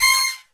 C6 POP FAL.wav